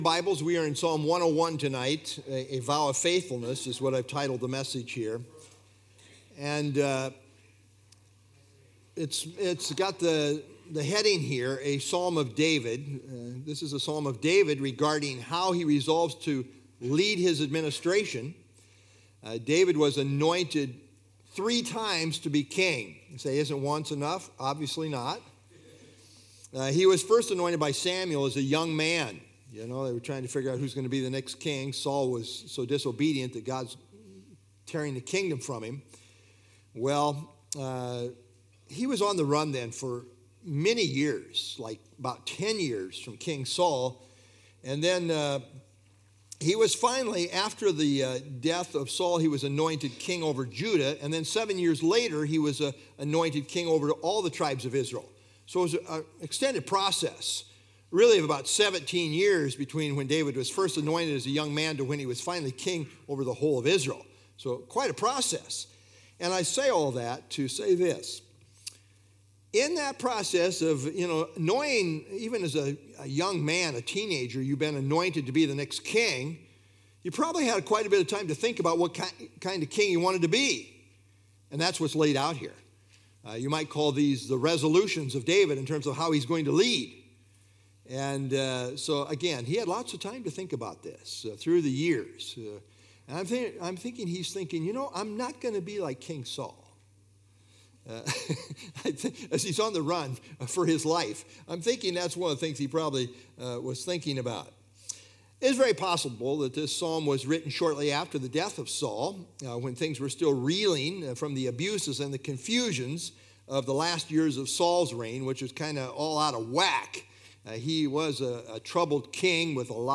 ( Sunday Evening )